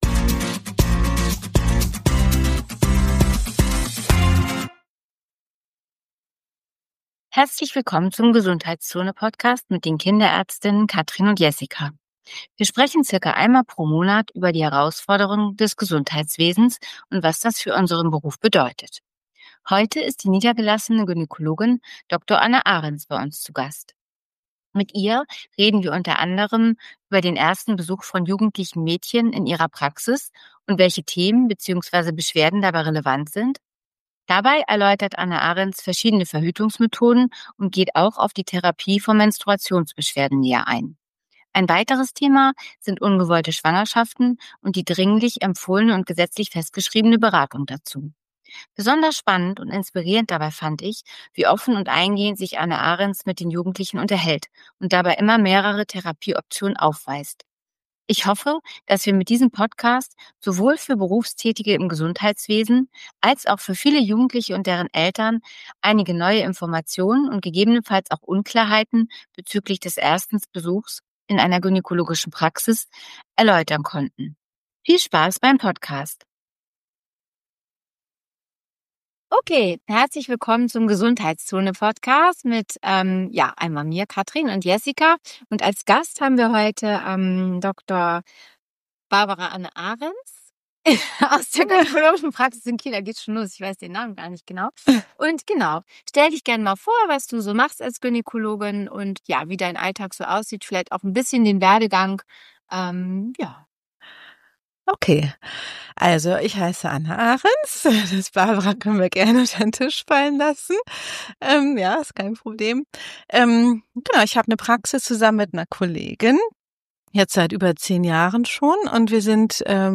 G#16 Eltern fragen, Teenager erleben- Gynäkolgin und Kinderärzte im Gespräch ~ GESUNDHEITSZONE // zwei Kinderärzte über Probleme und Lösungen in ihrem Job Podcast